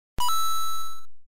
Index of /html/coin_sounds/